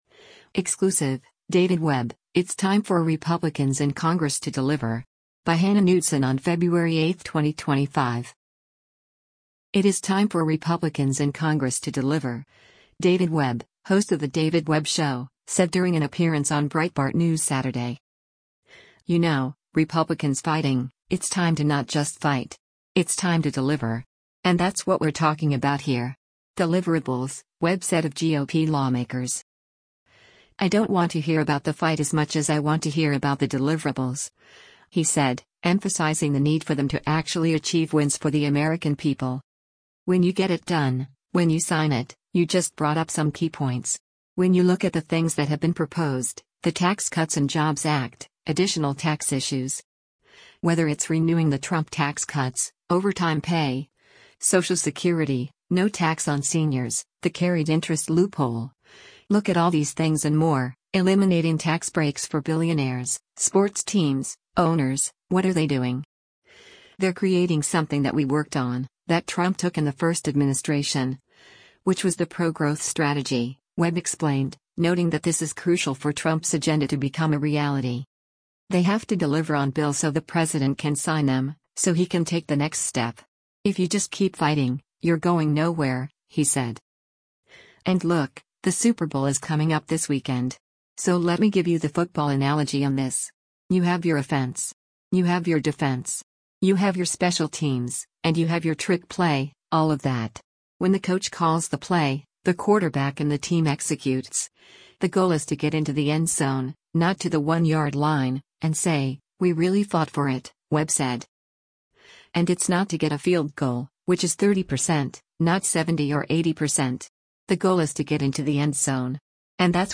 It is time for Republicans in Congress to “deliver,” David Webb, host of the David Webb Show, said during an appearance on Breitbart News Saturday.